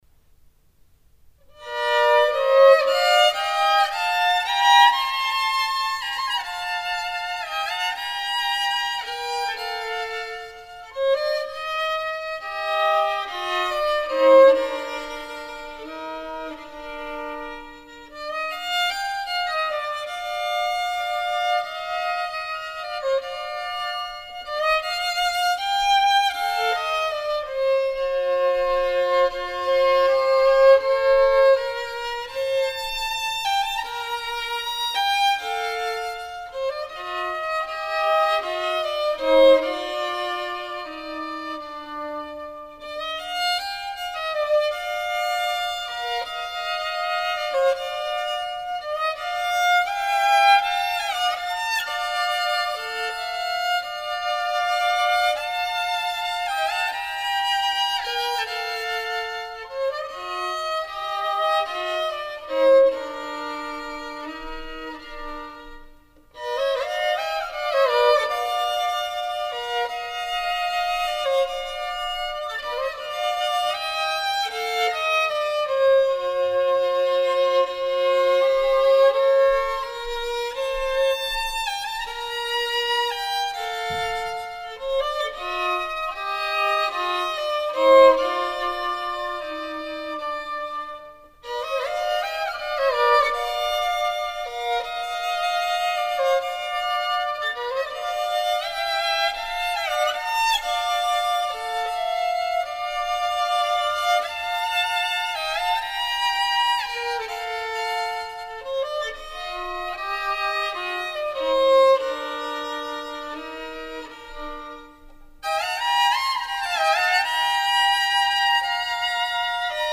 パッヘルベルのカノン以来の３度和音の集中攻撃（苦笑）。